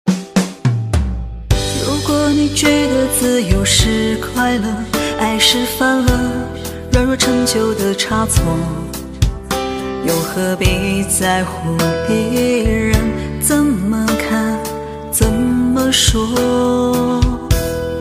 Nostalgic best Chinese songs